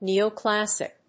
音節nèo・clássic 発音記号・読み方
/ˌnioˈklæsɪk(米国英語), ˌni:əʊˈklæsɪk(英国英語)/